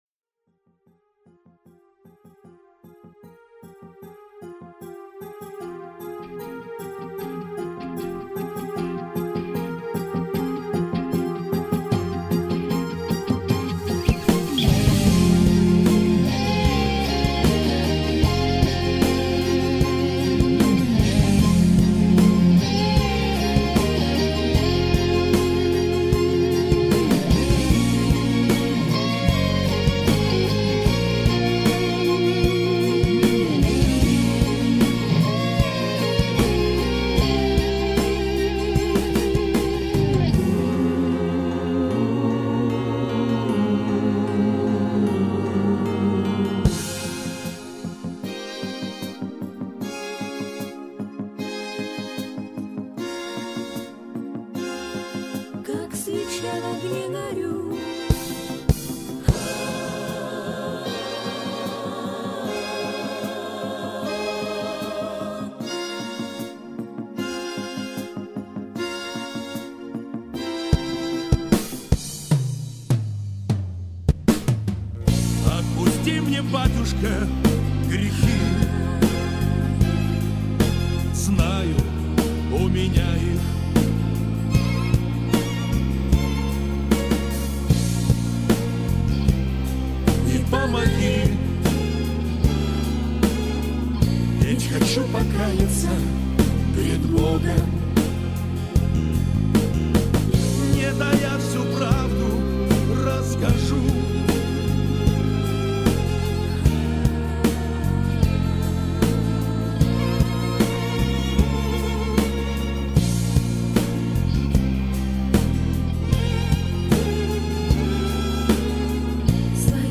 Пойте караоке
минусовка версия 55087